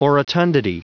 Prononciation du mot orotundity en anglais (fichier audio)
orotundity.wav